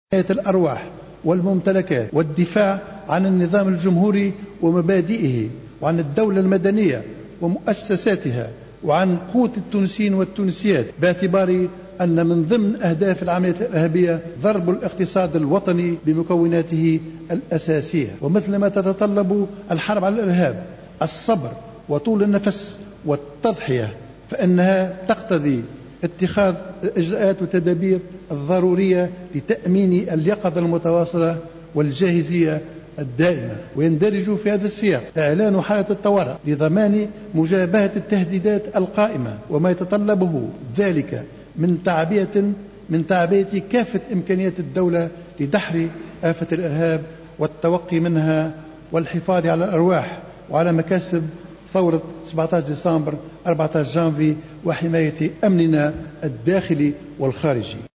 قال رئيس الحكومة الحبيب الصيد اليوم الأربعاء خلال جلسة استماع له بمجلس الشعب حول إعلان حالة الطوارئ والوضع الأمني عامة بالبلاد إن خطر الإرهاب مازال قائما وأنّ هنالك تهديدات وجب التوقي منها والتصدّي لها.